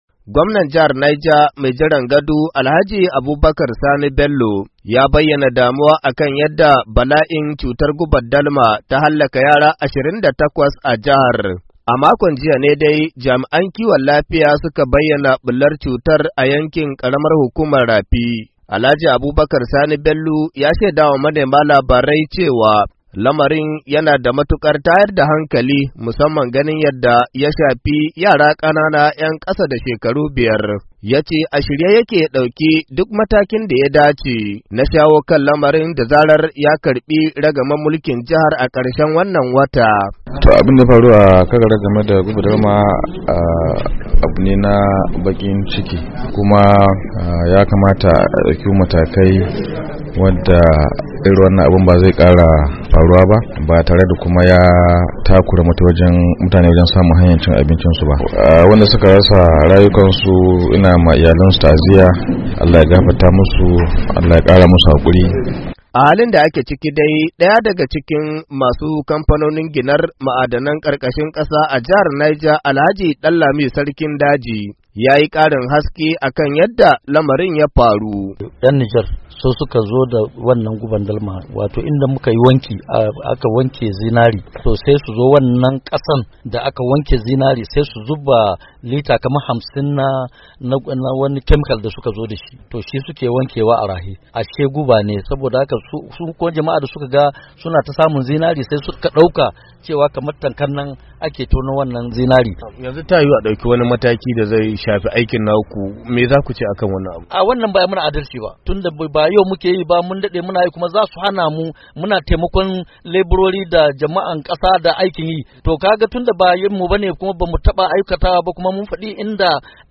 Ga rahoto